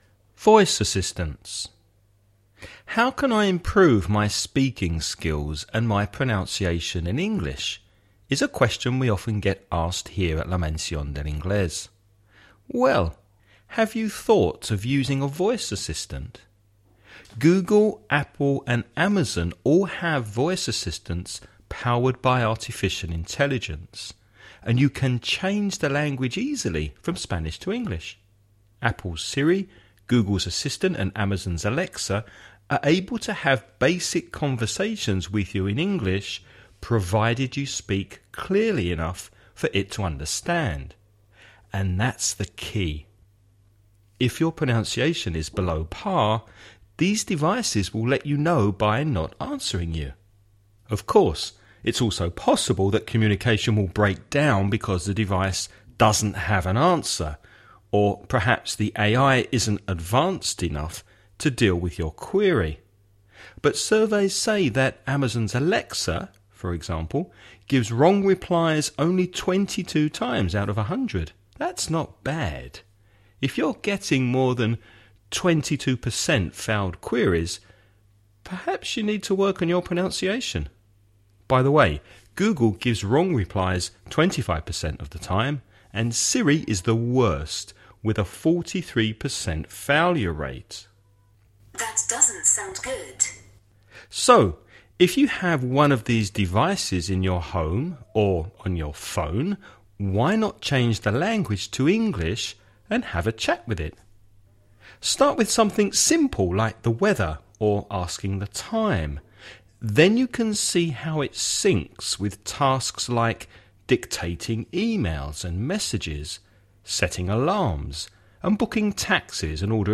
Listening Practice
Before you listen about a man talking about improving your English with voice assistants, read the following questions.